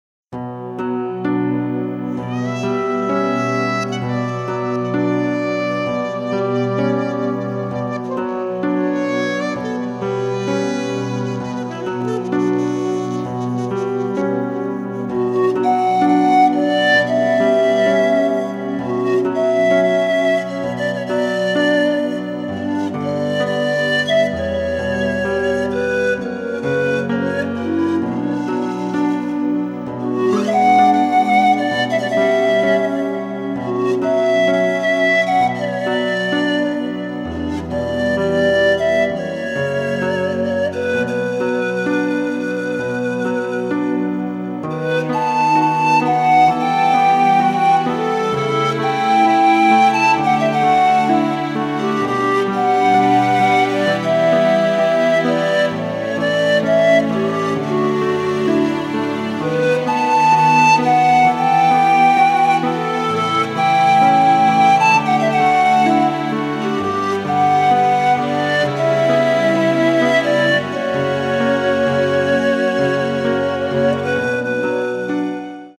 • Качество: 192, Stereo
красивые
спокойные
без слов
инструментальные
Флейта